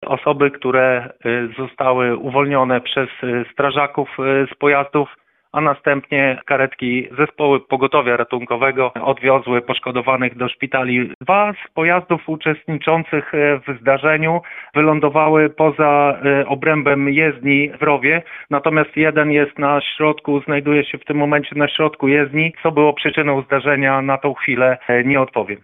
mówi w rozmowie z Radiem Lublin